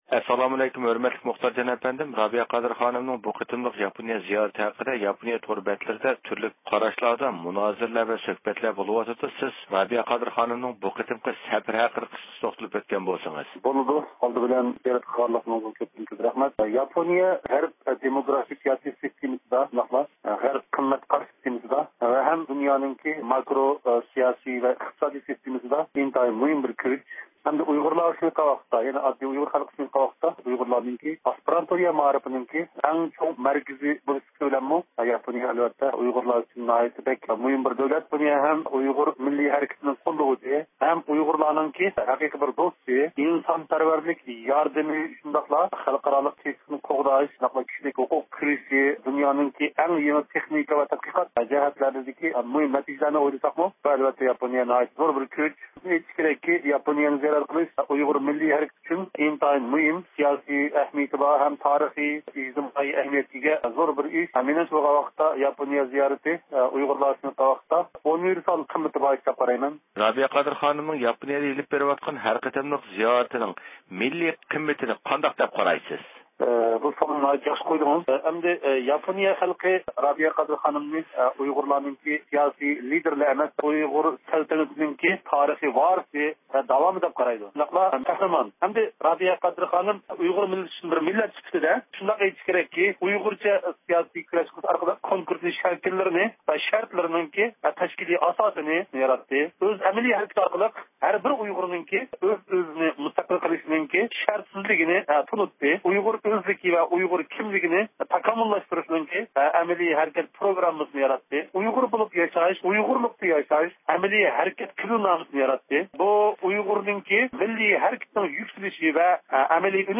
سۆھبىتىمىزدە